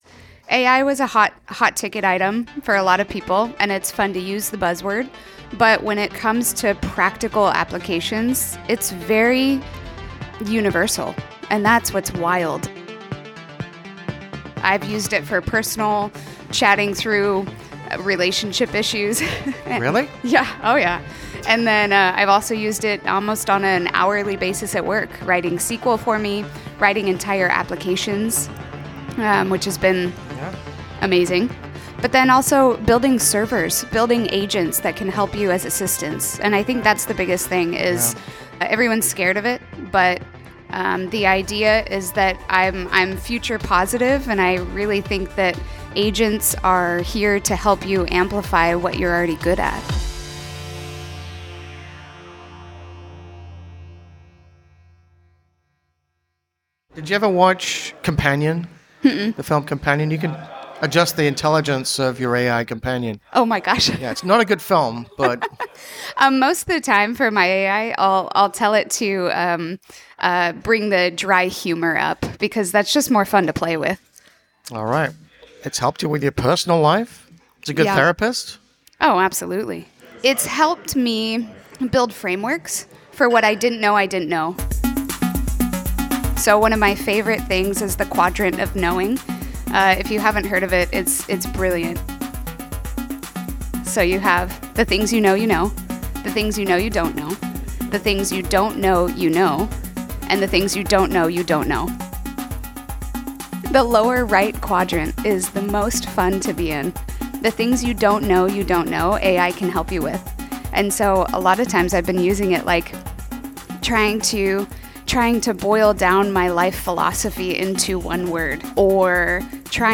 A conversation with Portland-based multi-dimensional artist